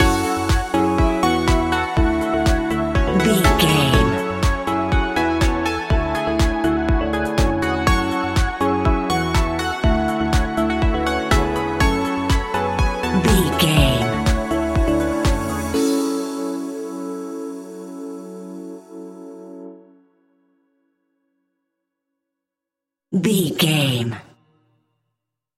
Aeolian/Minor
F#
groovy
cheerful/happy
piano
drum machine
synthesiser
electro house
funky house
synth leads
synth bass